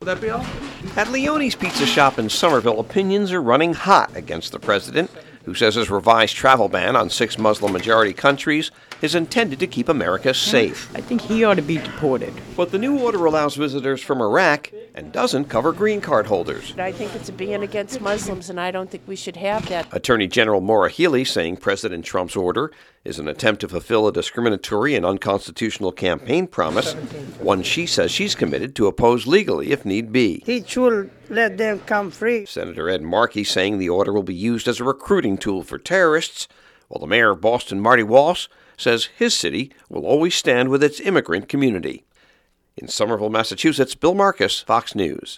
(BOSTON) MAR 6 – NEITHER THE PUBLIC NOR THE OFFICIALS WHO REPRESENT THEM IN MASSACHUSETTS SUPPORT THE PRESIDENT’S TRAVEL BAN. FOX NEWS RADIO’S